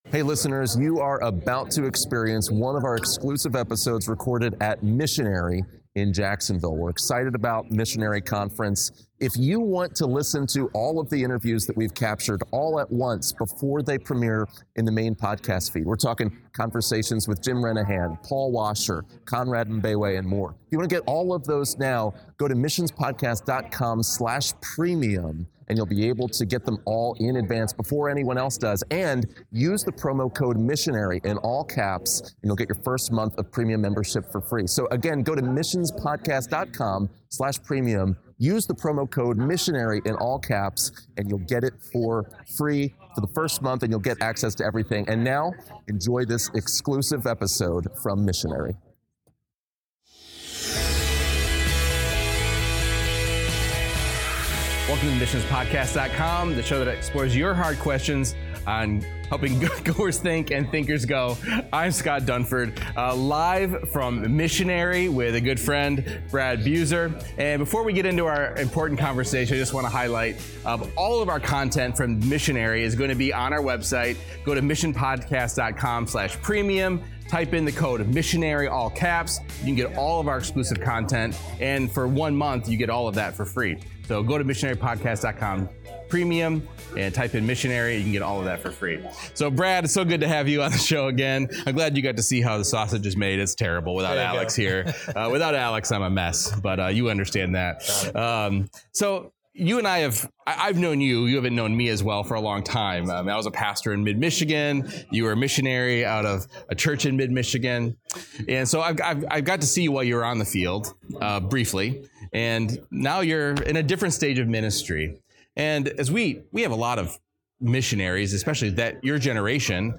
How do you make the most of ministry after the field? In this exclusive interview from Missionary